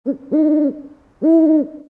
owl1.mp3